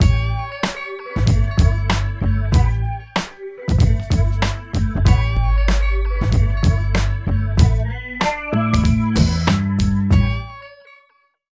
43 LOOP   -R.wav